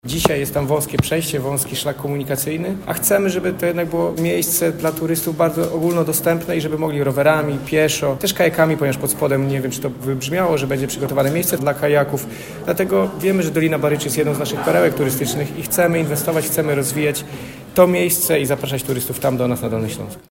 – Most wymaga remontu, a dodatkowo Milicz uzyska bardzo dobre połączenie z Doliną Baryczy – mówi Michał Rado, Wicemarszałek Województwa Dolnośląskiego.